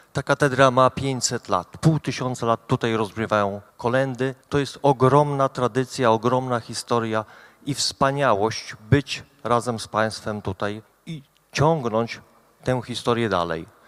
Zastępca wójta gminy Łomża Cezary Zborowski zwrócił uwagę na historię Katedry św. Michała Archanioła.